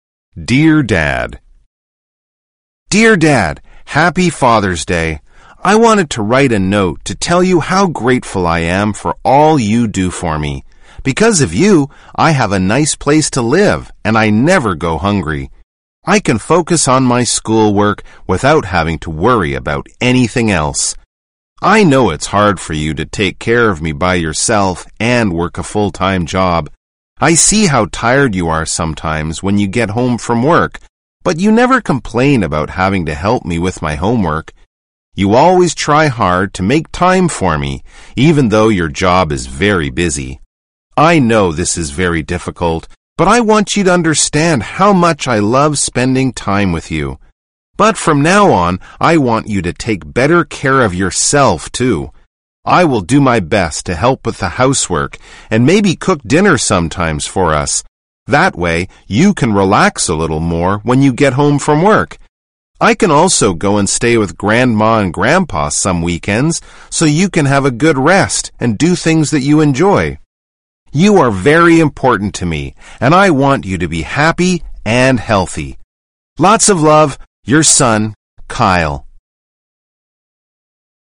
【口語會話 Follow me 】